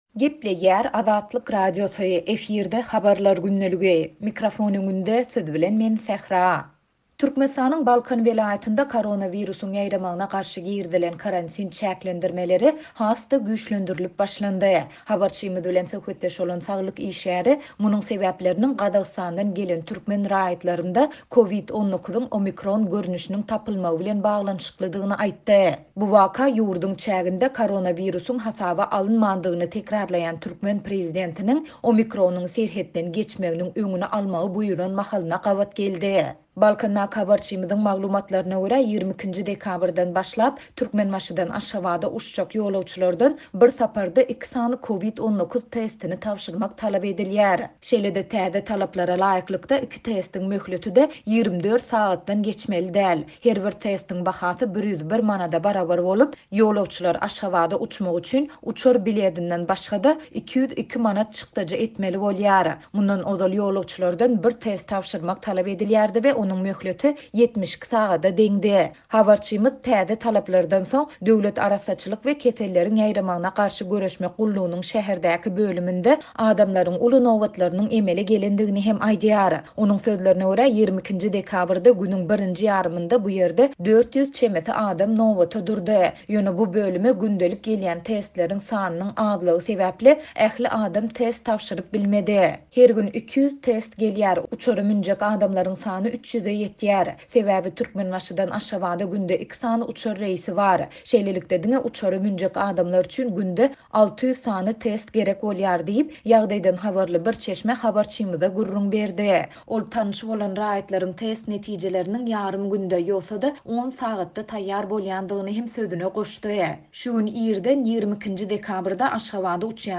Türkmenistanyň Balkan welaýatynda koronawirusyň ýaýramagyna garşy girizilen karantin çäklendirmeleri has-da güýçlendirilip başlandy. Habarçymyz bilen söhbetdeş bolan saglyk işgäri munuň sebäpleriniň Gazagystandan gelen türkmen raýatlarynda COVID-19-yň "omikron" görnüşiniň tapylmagy bilen baglanyşyklydygyny aýtdy.